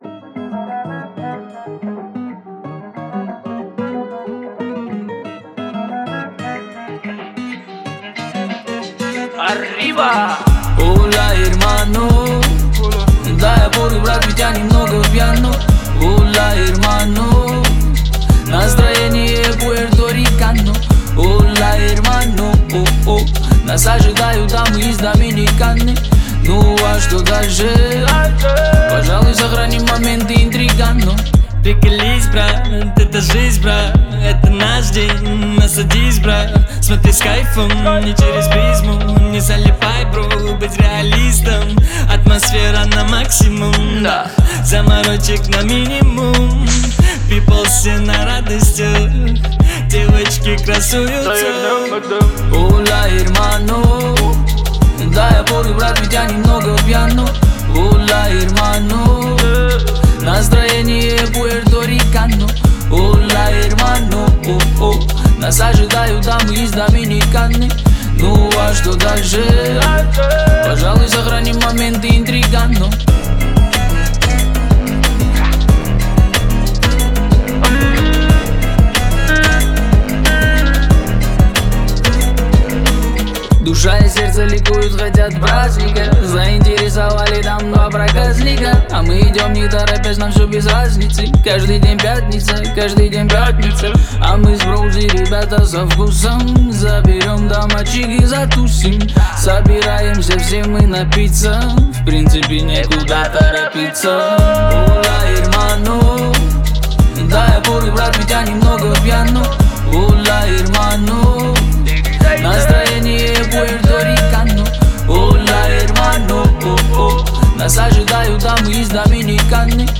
это энергичная композиция в жанре латинской поп-музыки